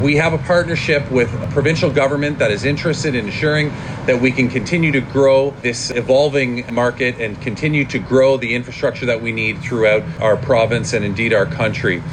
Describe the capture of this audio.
They gathered at the westbound Trenton ONroute to announce that fast chargers had been installed at all ONroutes, ahead of the summer tourist season.